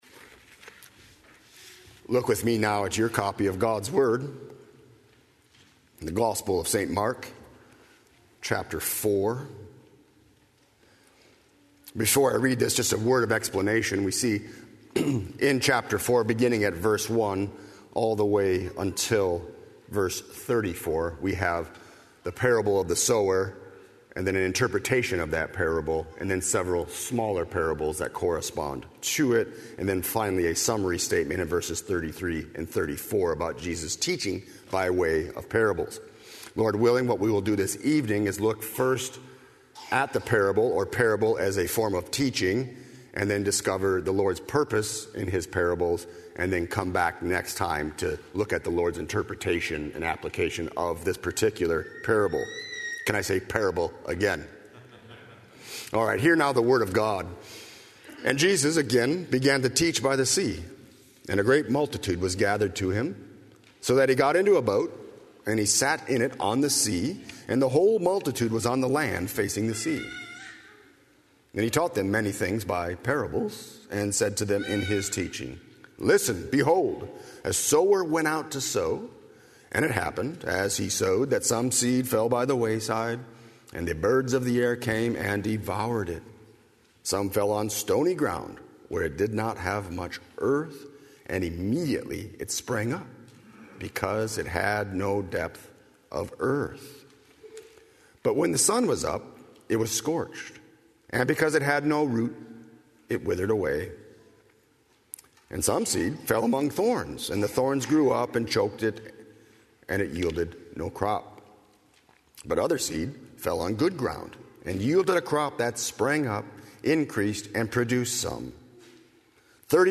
00:00 Download Copy link Sermon Text Mark 4:1–12